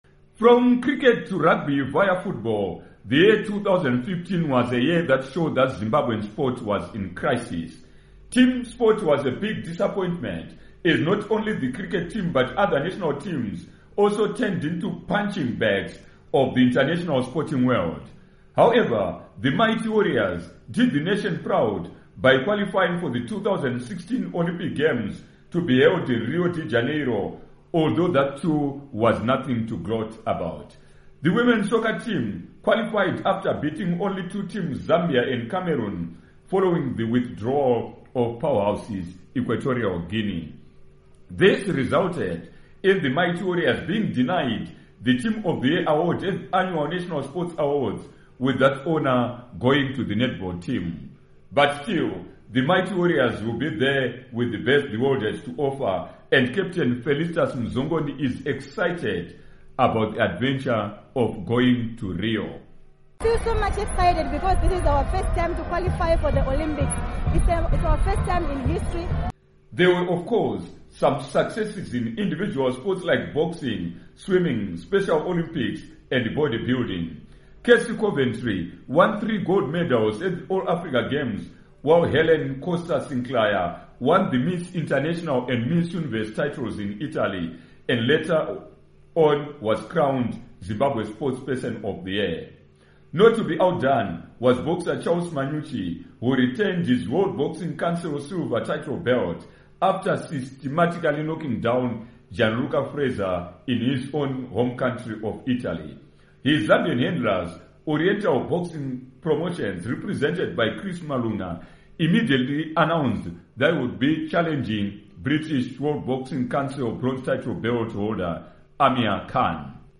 Report on Zimbabwe Sport